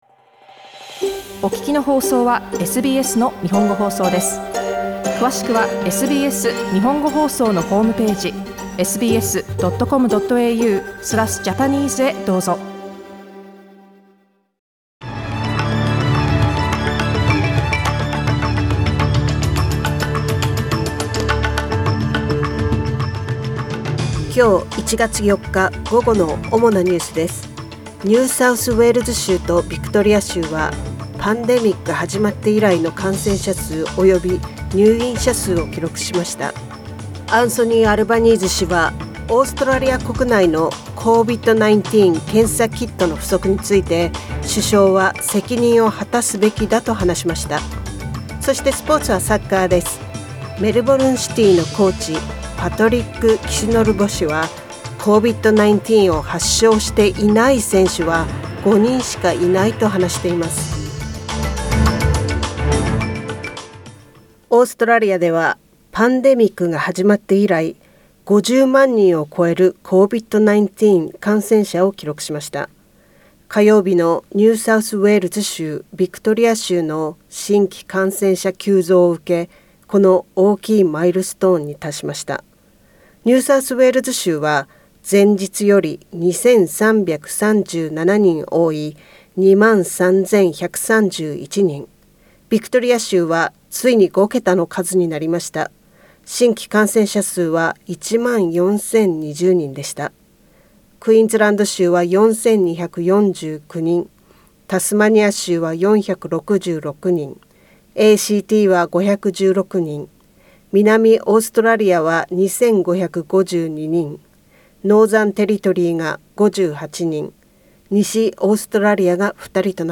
１月４日の午後のニュースです
Afternoon news in Japanese, 4 January 2022